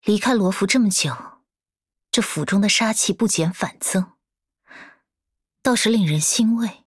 【模型】GPT-SoVITS模型编号044_女-secs